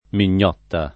[ min’n’ 0 tta ]